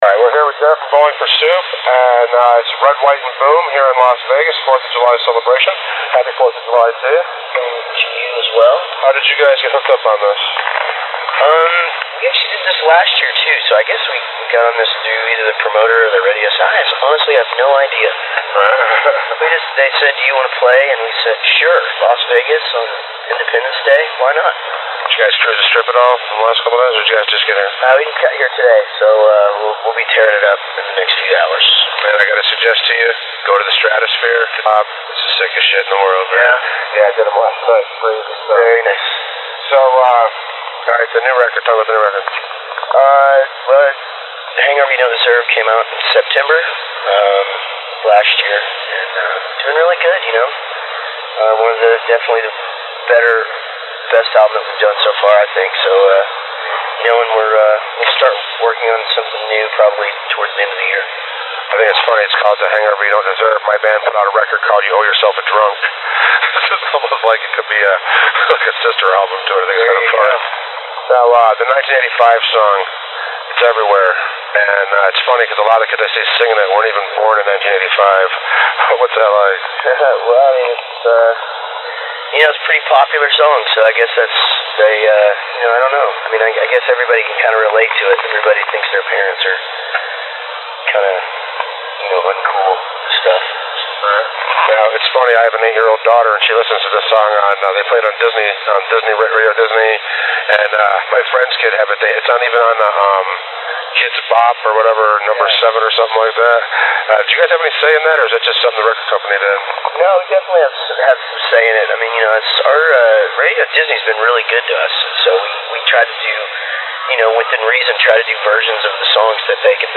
BFS-JaretChat.mp3